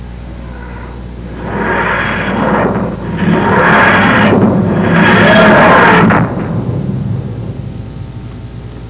Tardismaterializ.wav